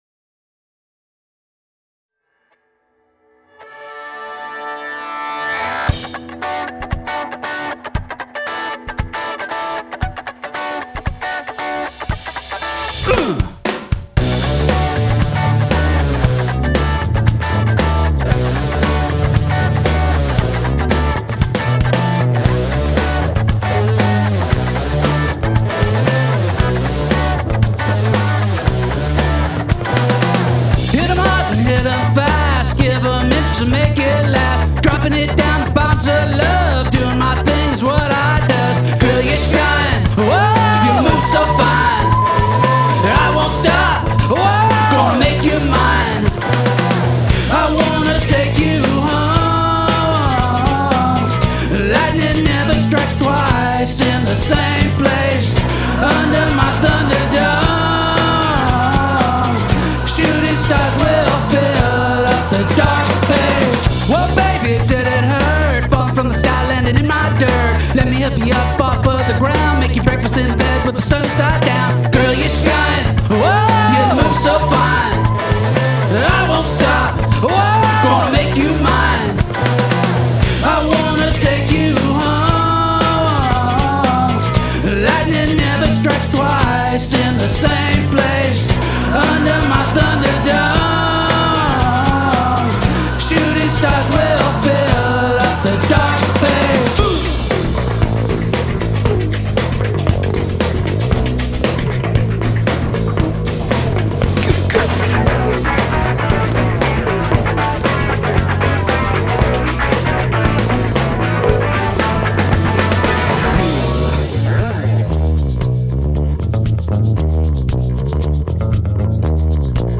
Use of choral voices